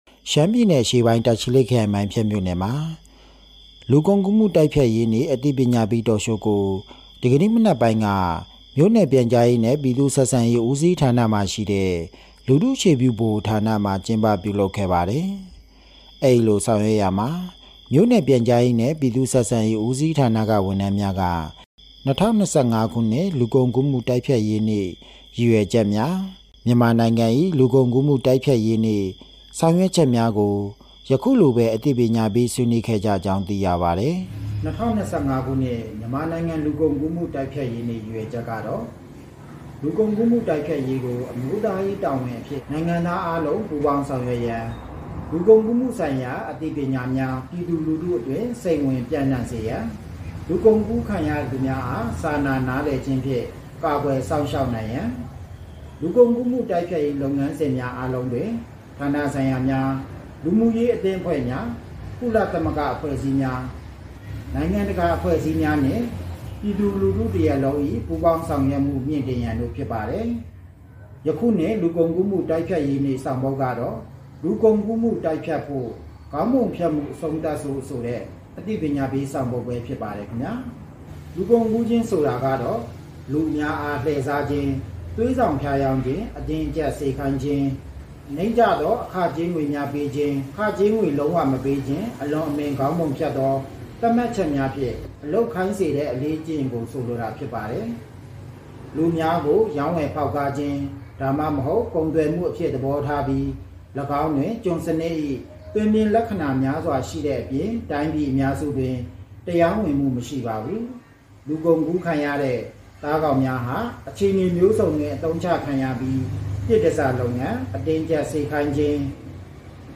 မိုင်းဖြတ်မြို့နယ်၌ လူကုန်ကူးမှုအန္တရာယ် (Talk Show)ပြုလုပ်